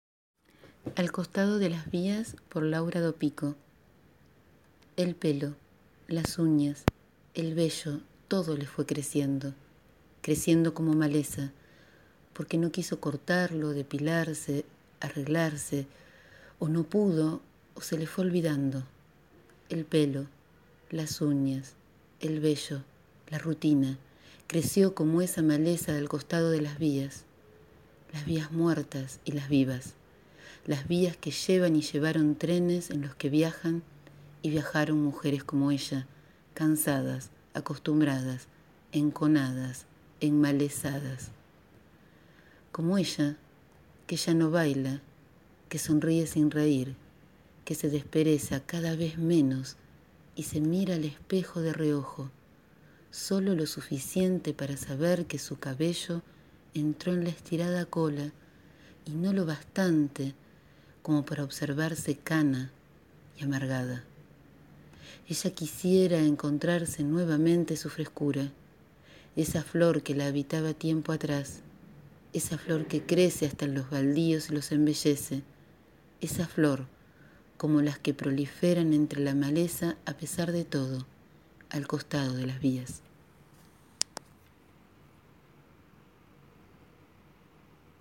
La semana pasada me encontré que este texto que hoy les leo. Un texto donde lo que queda al margen, al costado, en el borde, casi desecho, cobra escena y sin disimular ninguna de las condiciones porta belleza.